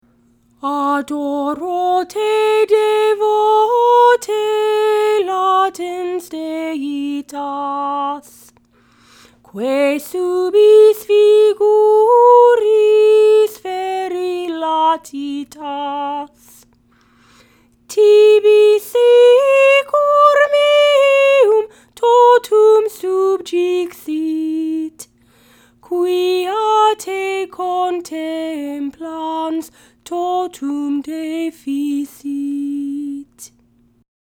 “Adoro Te Devote”  Text: St. Thomas Aquinas, 1264 Tune: Adoro Te Devote Sing along with me to learn verse one of this hymn.